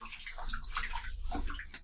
户外 " 水流
描述：在室内环境中流动的水（可听见的混响）。
Tag: 液体 流量 室内 混响